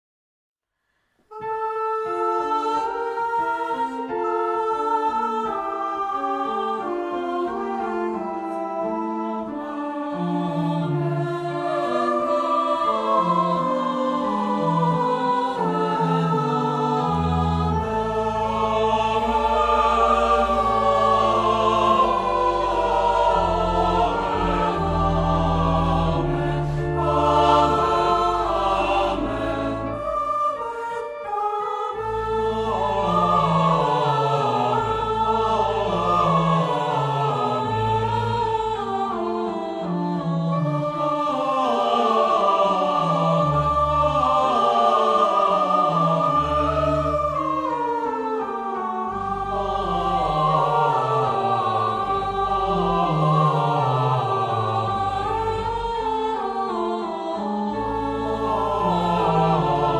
Género/Estilo/Forma: Sagrado ; Aclamacion
Tipo de formación coral: SAH  (3 voces Coro mixto )
Instrumentos: Organo (1)
Tonalidad : re menor